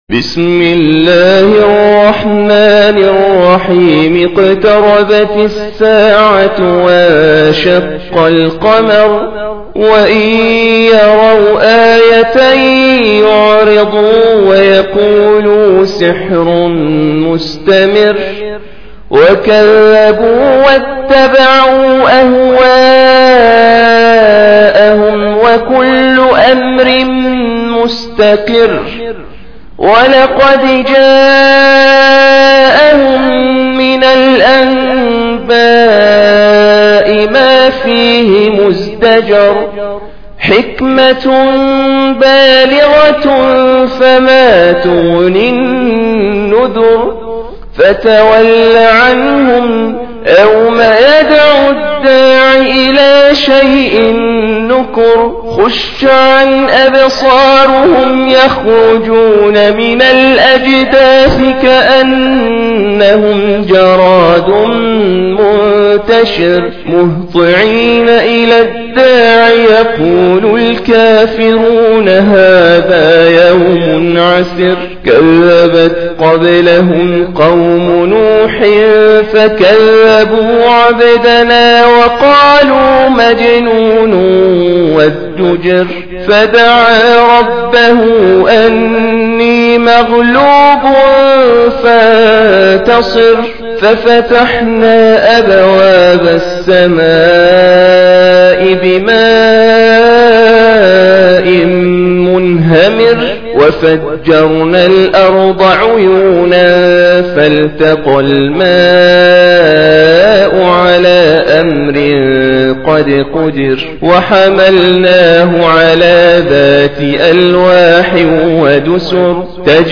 54. Surah Al-Qamar سورة القمر Audio Quran Tarteel Recitation
Surah Sequence تتابع السورة Download Surah حمّل السورة Reciting Murattalah Audio for 54.